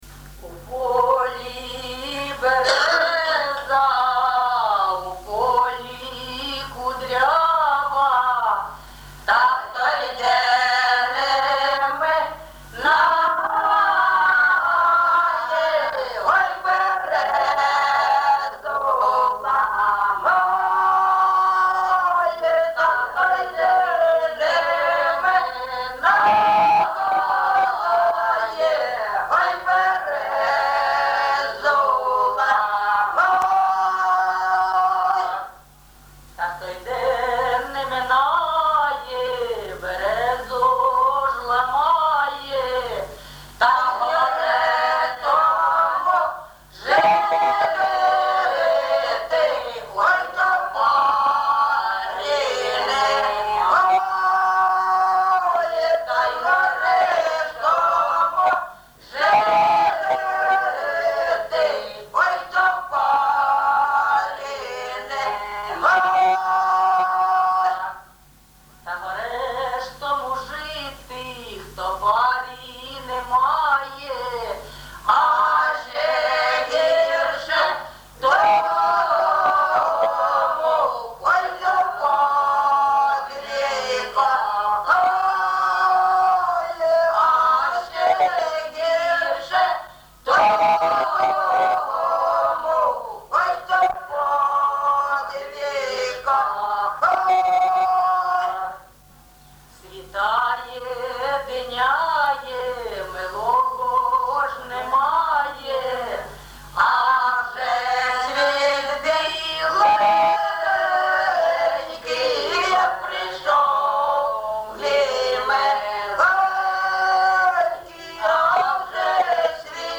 ЖанрПісні з особистого та родинного життя
МотивЗрада, Перелюб, Шлюбні стосунки, Журба, туга
Місце записум. Єнакієве, Горлівський район, Донецька обл., Україна, Слобожанщина